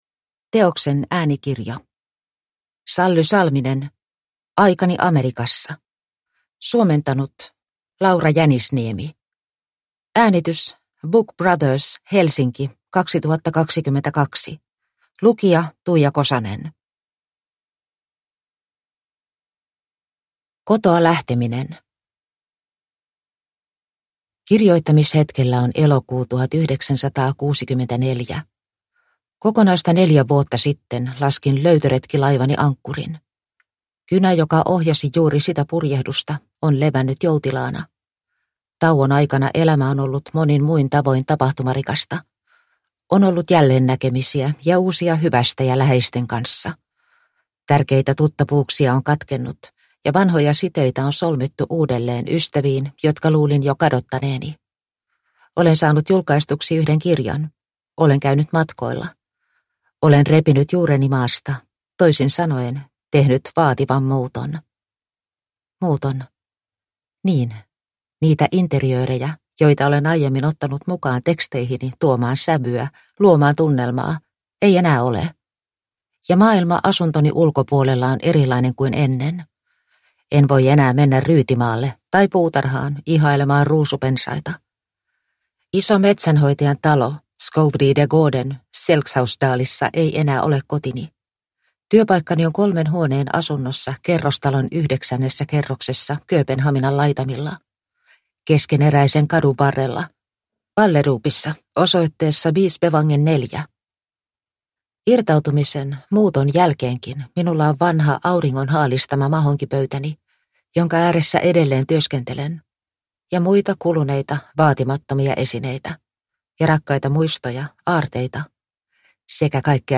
Aikani Amerikassa – Ljudbok – Laddas ner